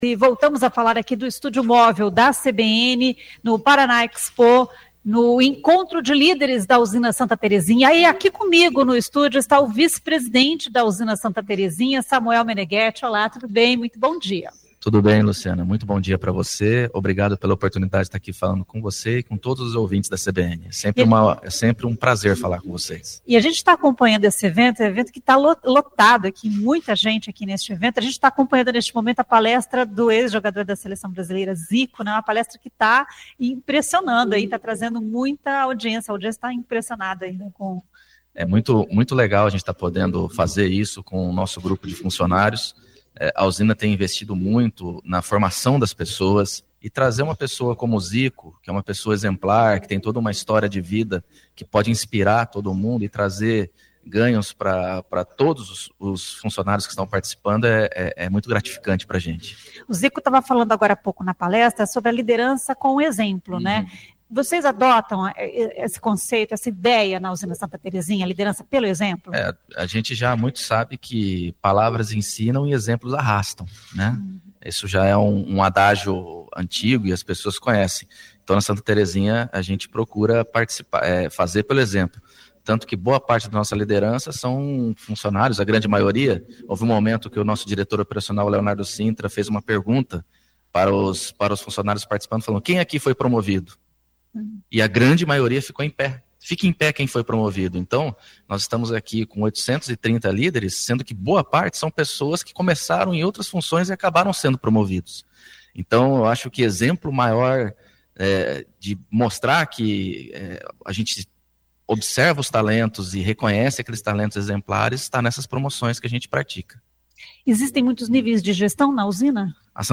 A entrevista foi realizada no Estúdio Móvel CBN, instalado na Paraná Expo, onde ocorre o Encontro de Líderes promovido pela Usina Santa Terezinha.